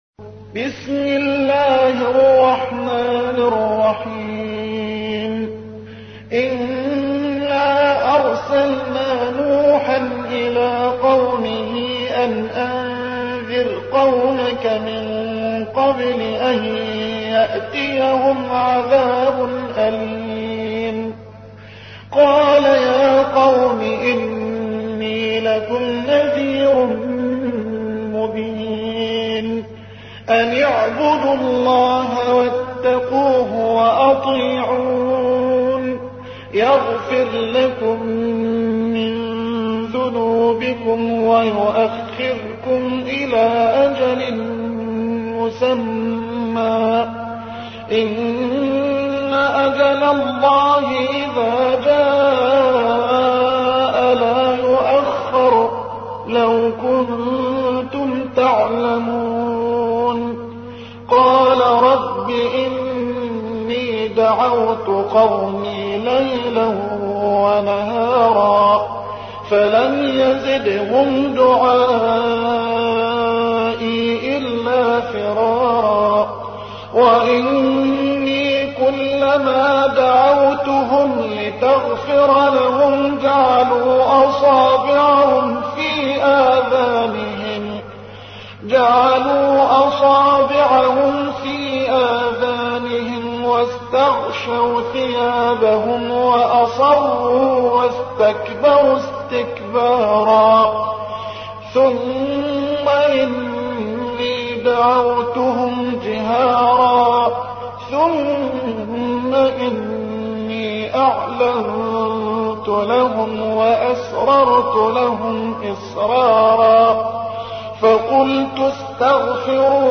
71. سورة نوح / القارئ